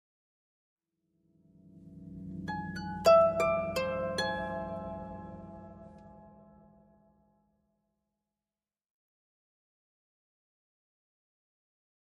Harp, Arpeggio Reminder, Type 3